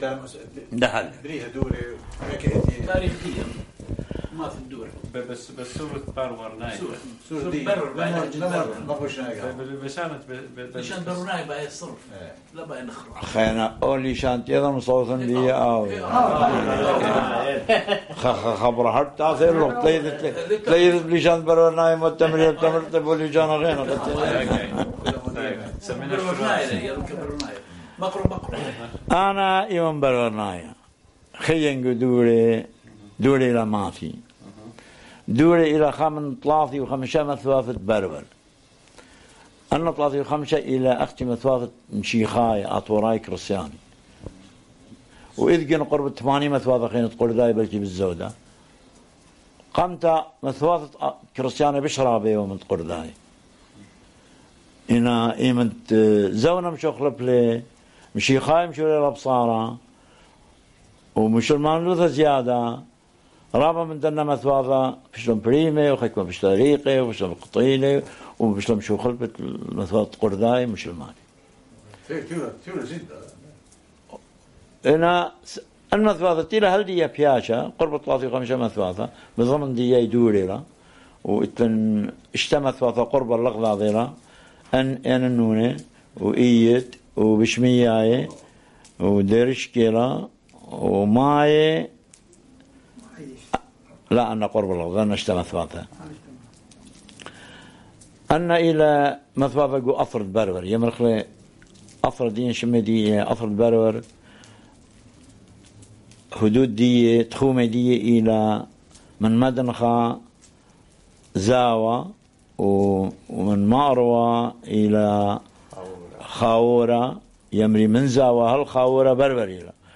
The North-Eastern Neo-Aramaic Database Project